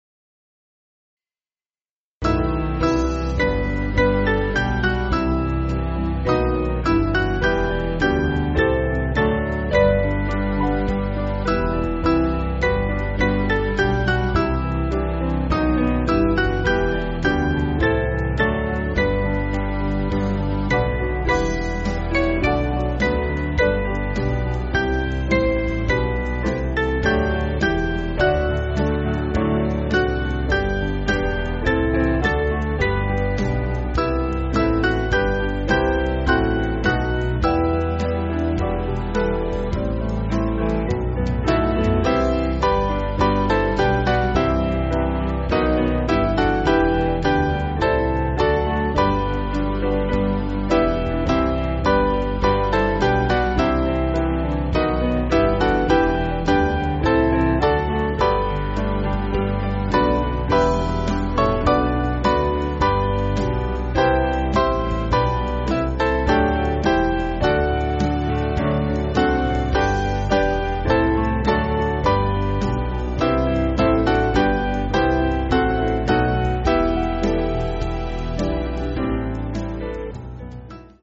Small Band
(CM)   4/Em